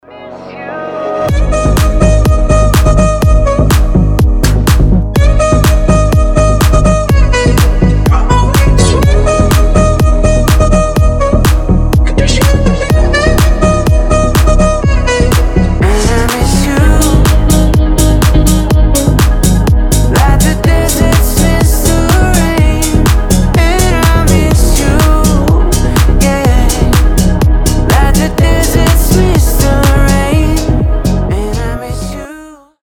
• Качество: 320, Stereo
скрипка
house